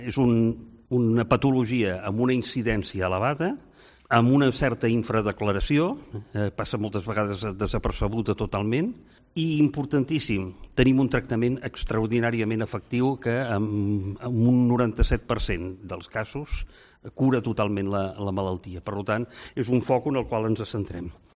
Declaraciones del Secretario de Salud Pública, Joan Guix